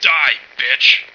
flak_m/sounds/male1/int/M1diebitch.ogg at 602a89cc682bb6abb8a4c4c5544b4943a46f4bd3